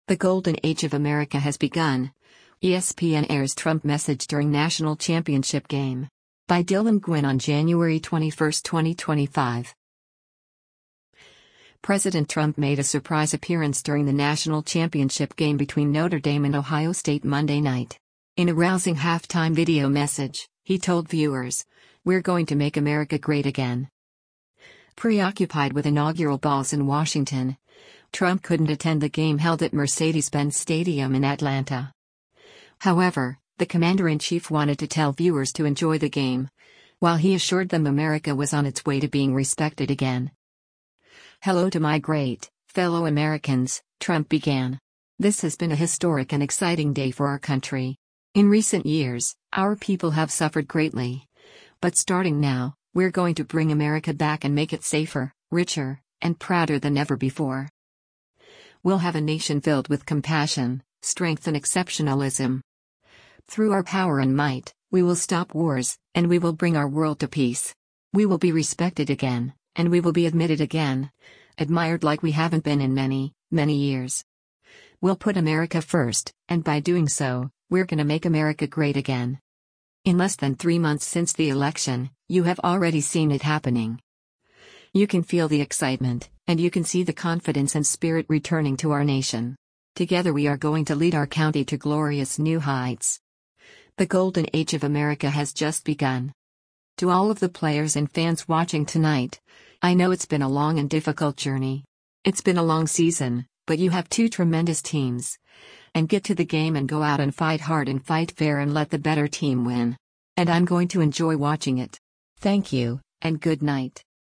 President Trump made a surprise appearance during the national championship game between Notre Dame and Ohio State Monday night. In a rousing halftime video message, he told viewers, “We’re going to make America great again.”